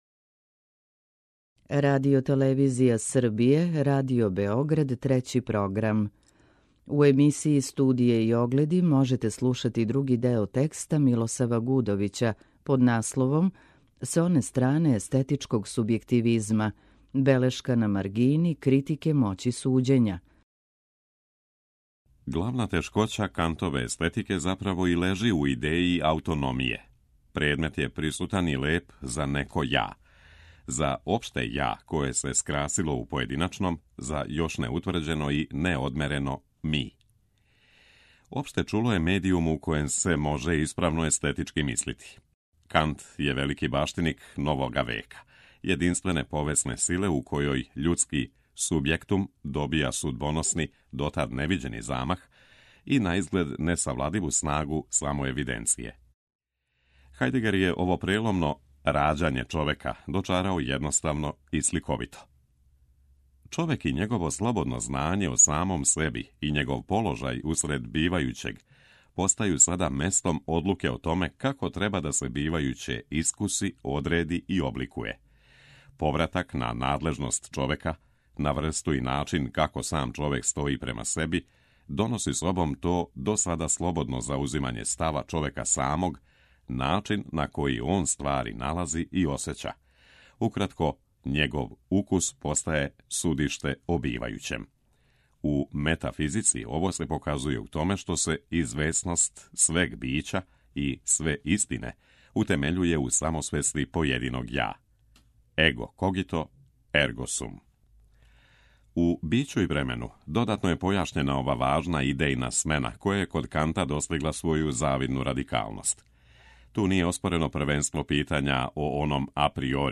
Белешка на маргини ’Критике моћи суђења’”. преузми : 9.83 MB Тема недеље Autor: Редакција Прва говорна емисија сваке вечери од понедељка до петка.